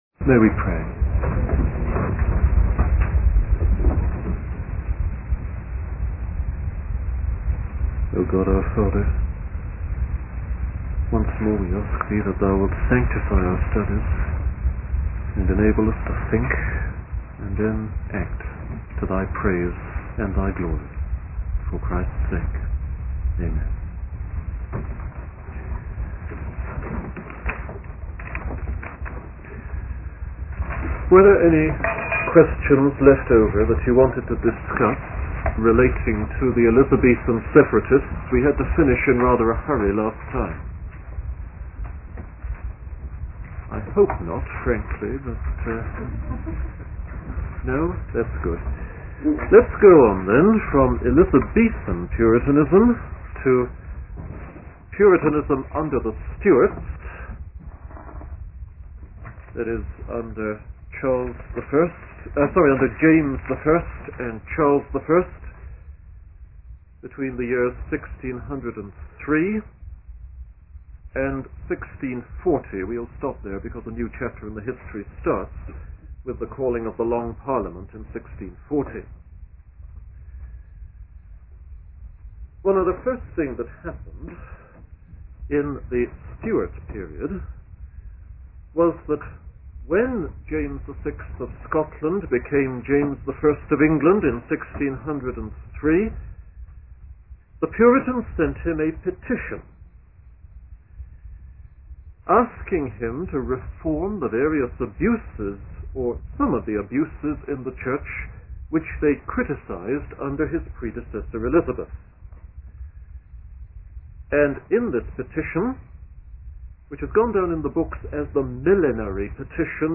The sermon transcript discusses the background of the Puritan preachers who were educated at Cambridge colleges.